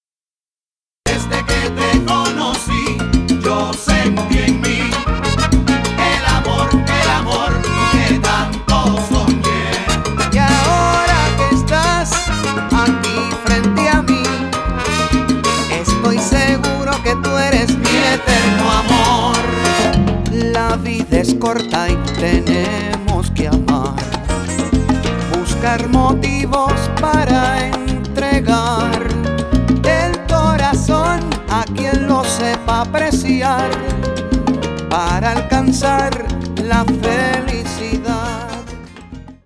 LATIN TROPICAL SOUL EXPERIENCE